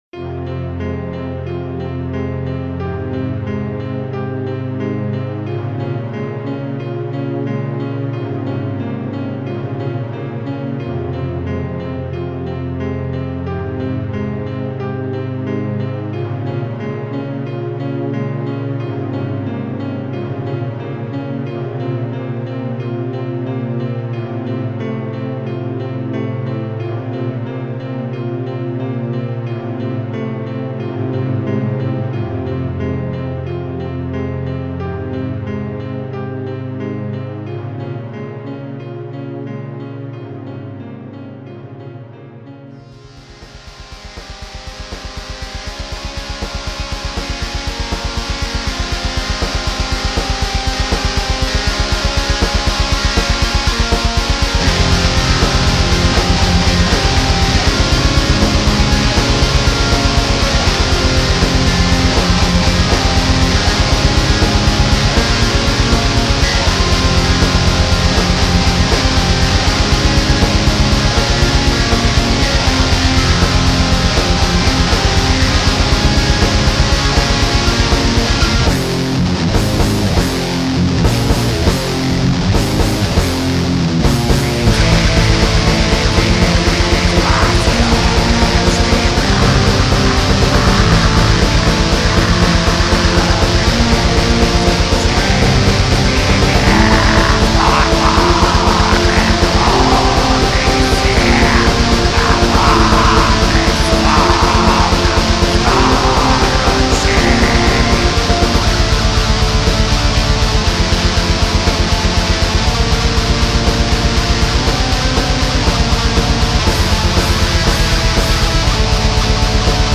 melodic metal